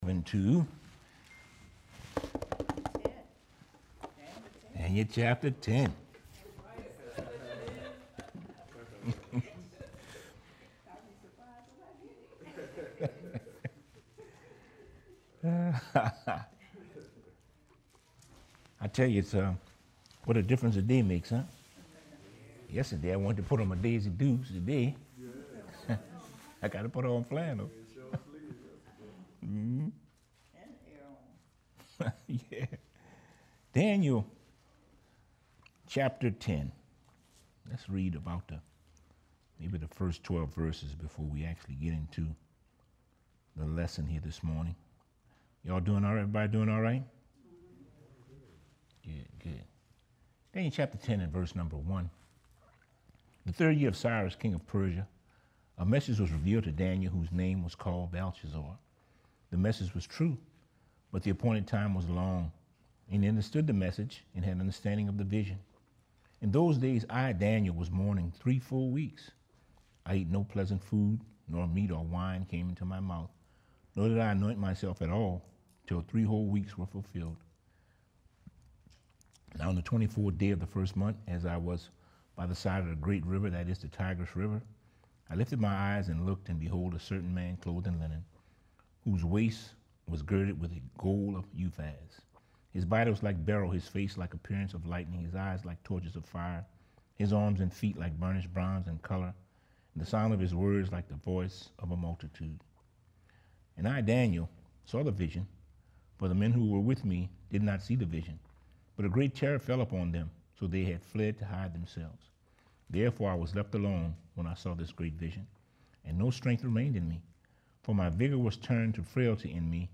bible class